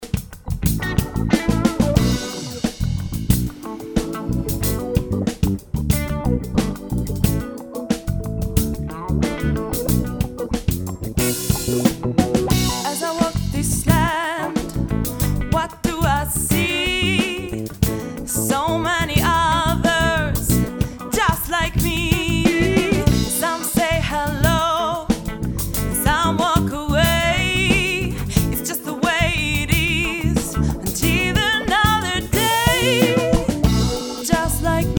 In Anhang ein kleines Beispiel. Die unbearbeiteten Spuren vor dem Downmix und mit etwas überbetontem Bass Anhänge Just like me_1Ausschnitt.mp3 819,2 KB